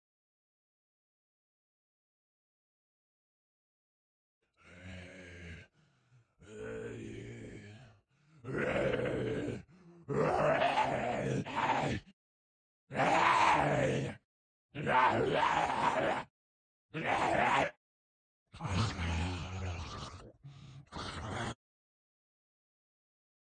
Kyma僵尸 " 僵尸Kyma表演02 - 声音 - 淘声网 - 免费音效素材资源|视频游戏配乐下载